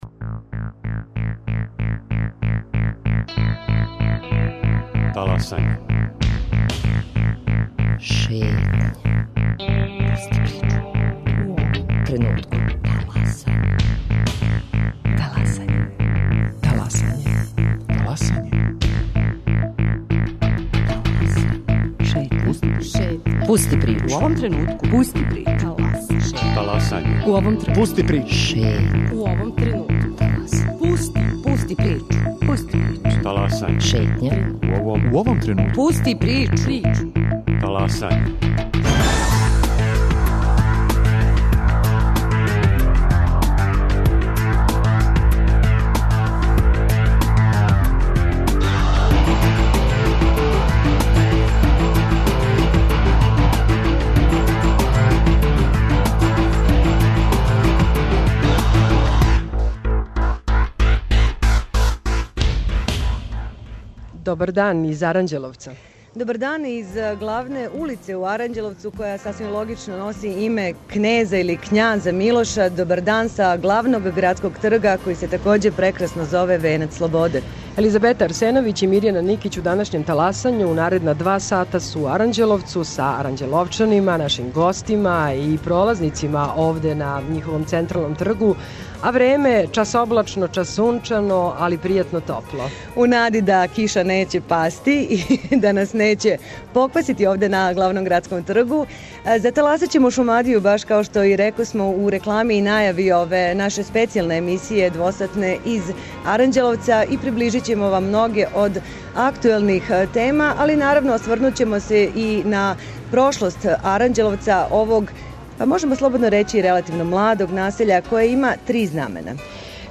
Емисија 'Таласање' Радио Београда 1 емитује се уживо са главног градског трга у Аранђеловцу!